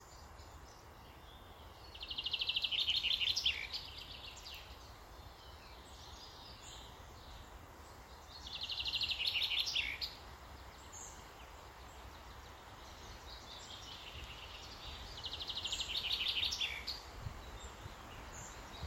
Chaffinch, Fringilla coelebs
Administratīvā teritorijaRīga
StatusSinging male in breeding season